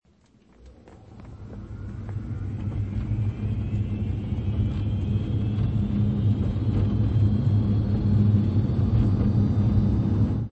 Звуки электромобиля
Звук мотора электрокара Тесла